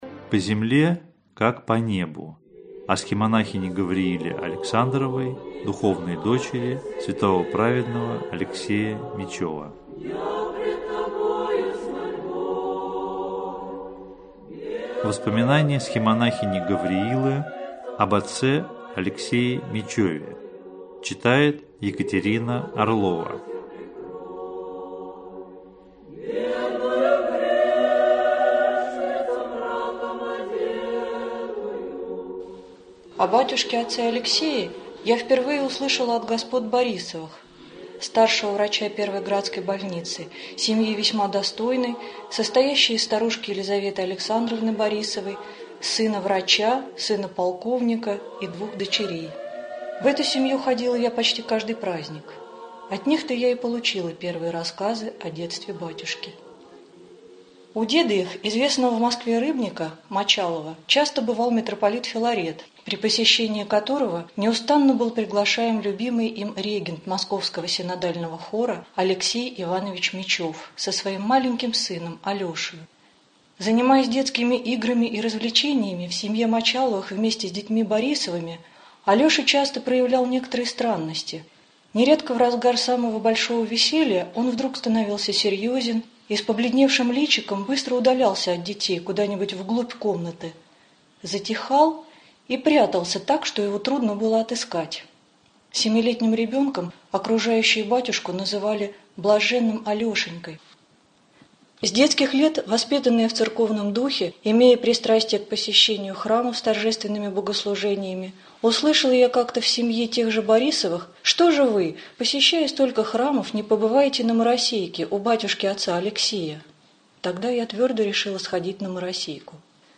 Aудиокнига По земле как по небу Автор Схимонахиня Гавриила